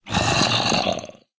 sounds / mob / zombie / say2.ogg